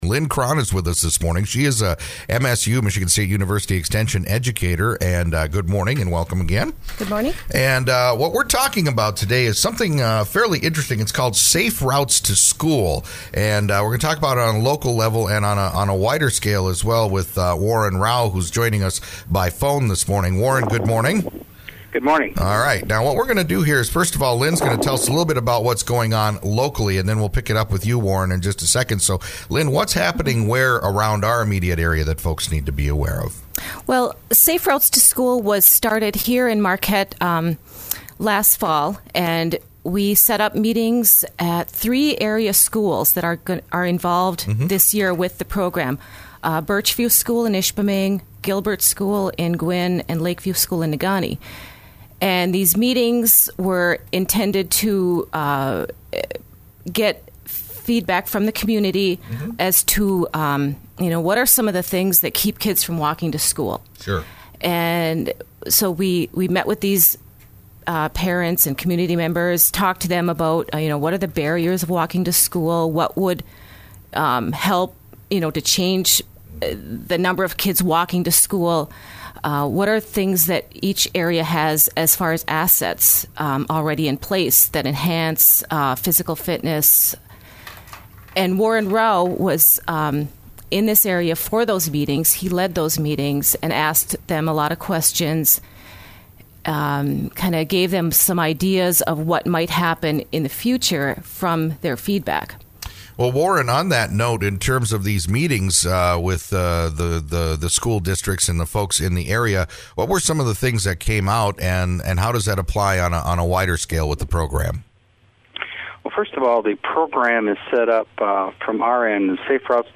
KIDSDiscussion about this initiative that has been happening at three area schools in Marquette County, Birchview Elementary in Ishpeming, Lakeview Elementary in Negaunee, and Gilbert Elementary in Gwinn. The goal of Safe Routes to School is to remove the barriers that prevent school children from walking or biking to school by creating safe, walk-able routes to school.